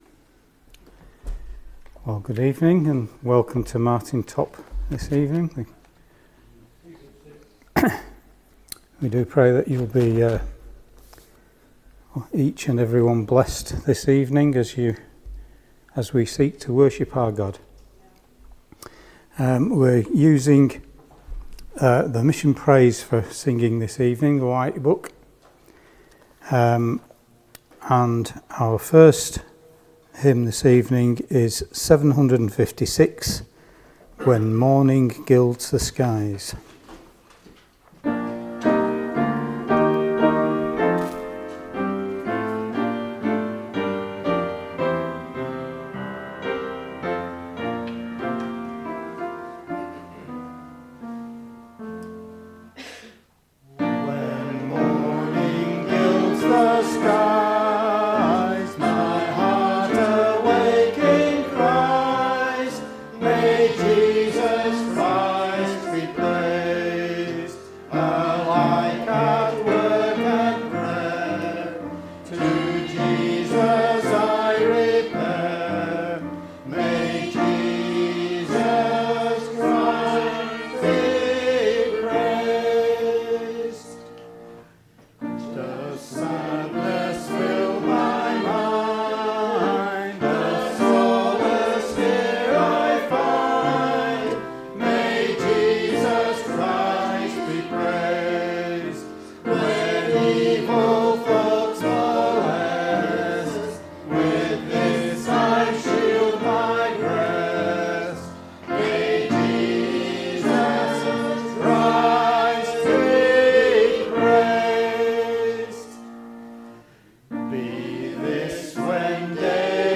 Below is audio of the full service.
2025-10-26 Evening Worship If you listen to the whole service on here (as opposed to just the sermon), would you let us know?